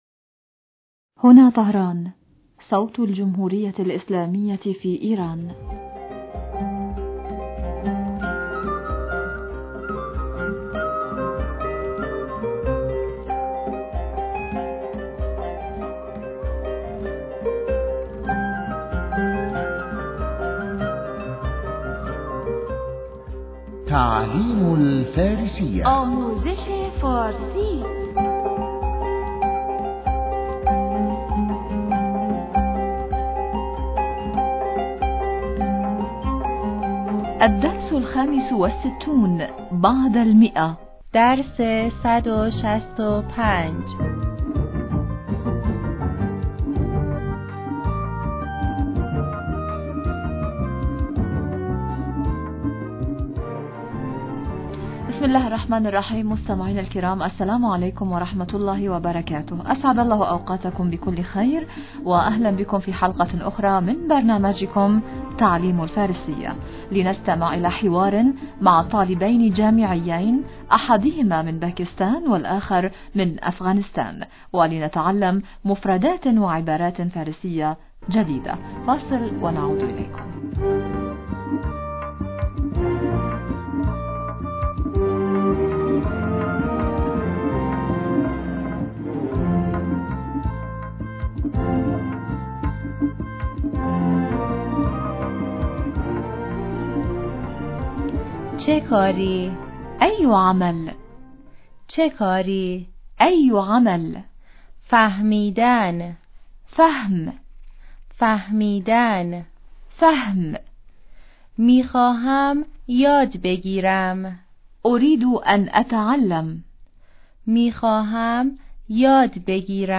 أسعد الله أوقاتكم بكل خير وأهلاً بكم أعزائي المستمعين في حلقة أخري من برنامجكمْ لنستمع إلي حوار مع طالبين جامعيين أحدهما من باكستان والآخر من أفغانستان./ ولنتعلّم مفردات وعبارات فارسية جديدة.